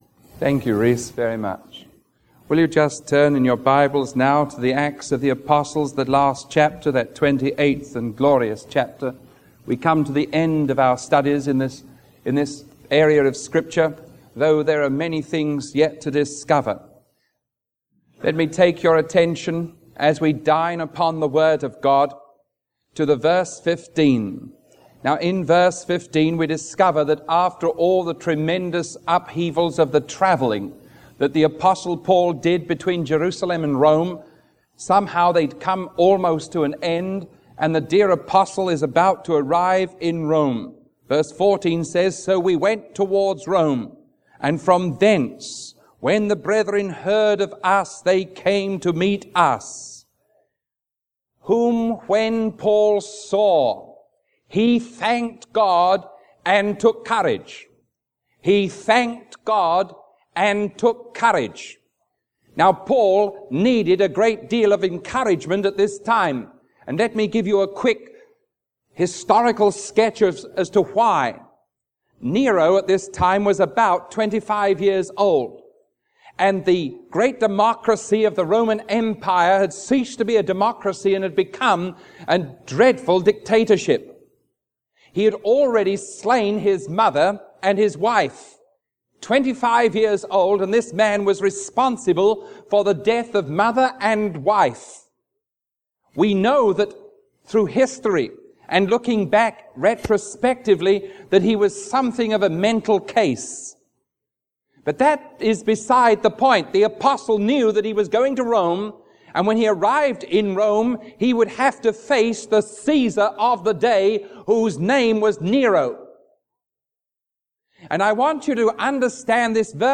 Series: Watsonville